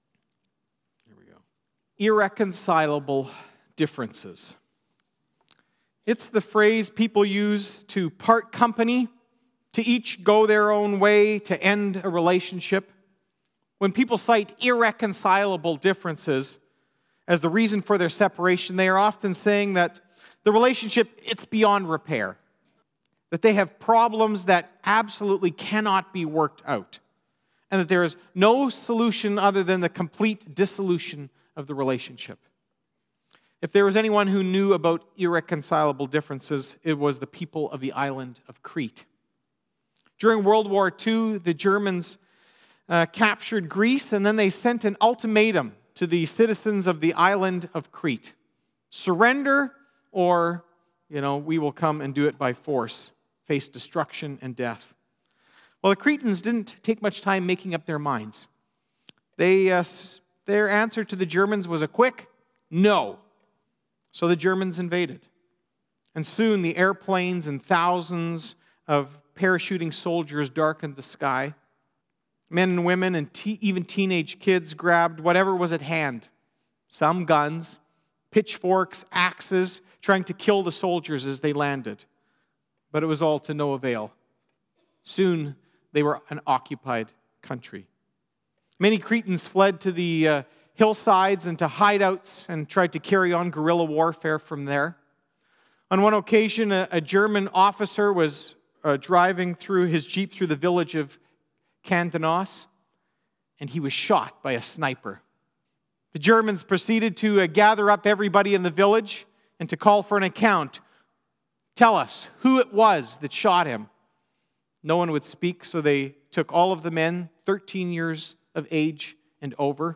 Sermons | Eagle Ridge Bible Fellowship